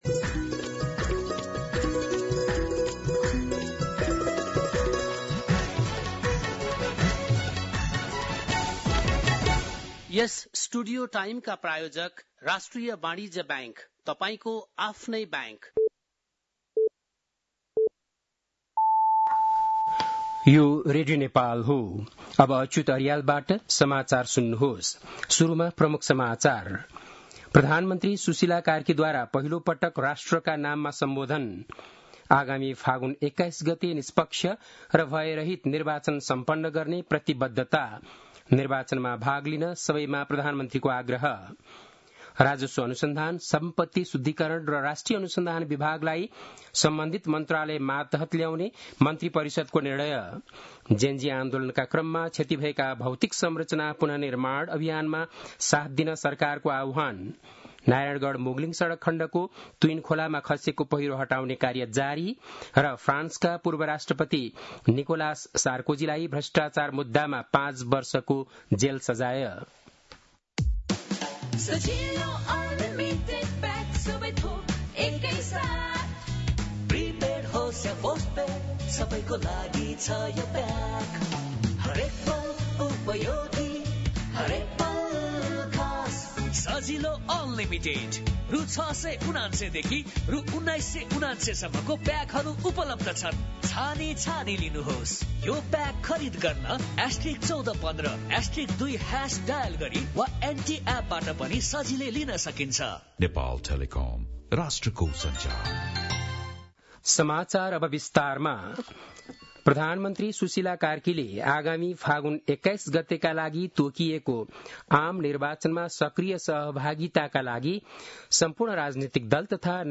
An online outlet of Nepal's national radio broadcaster
बेलुकी ७ बजेको नेपाली समाचार : ९ असोज , २०८२
7-pm-nepali-news-.mp3